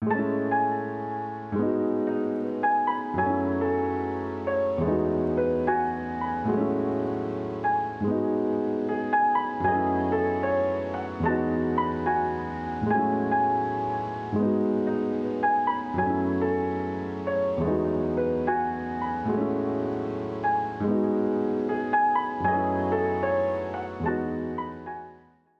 jazz keys 3.wav